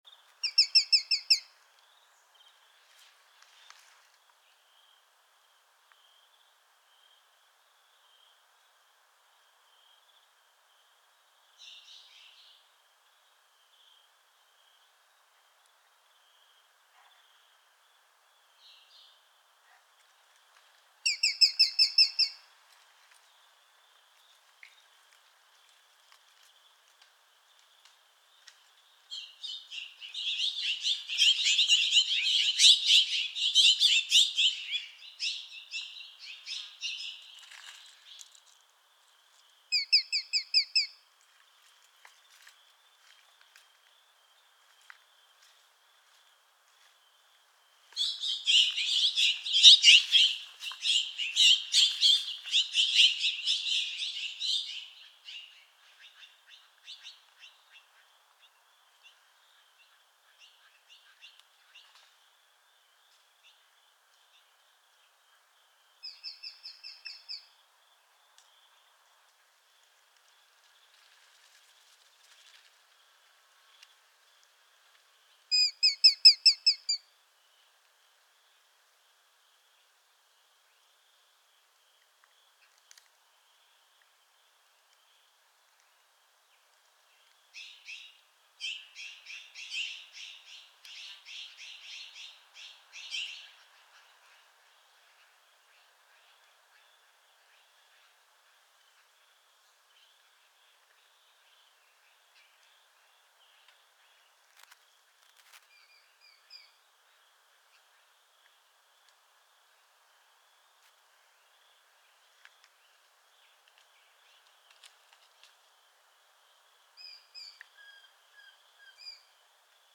4. Sharp-Shinned Hawk (Accipiter striatus)
Call: High-pitched, rapid “kik-kik-kik.”